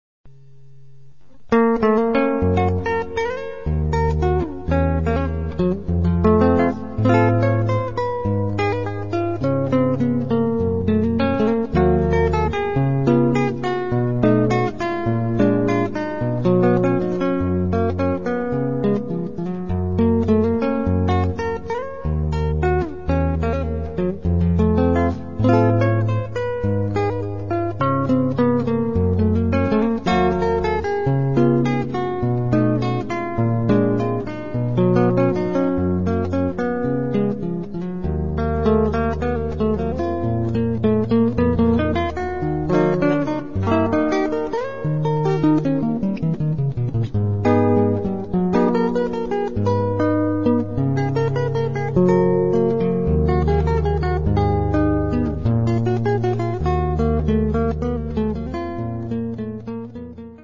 in Brasilian styles
Samba-canção